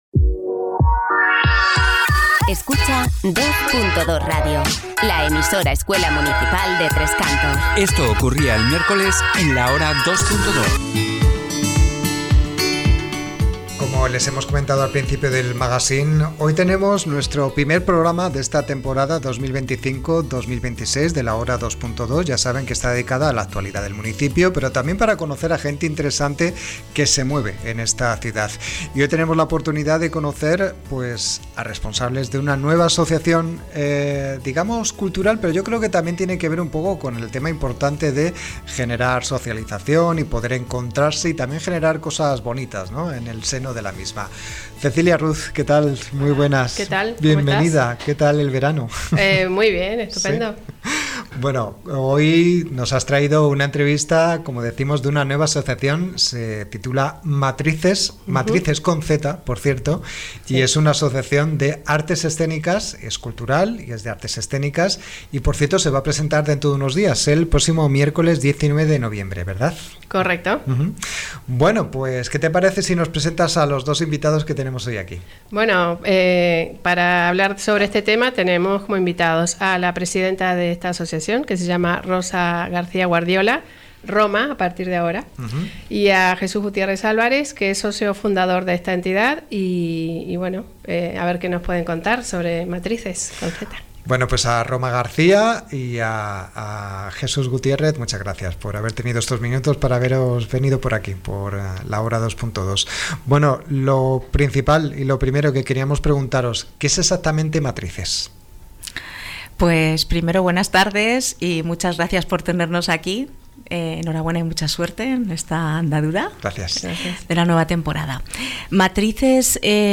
Entrevista-Asociacion-de-artes-escenicas-Matizes.mp3